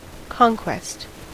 Ääntäminen
US : IPA : [kɑŋkwɛst]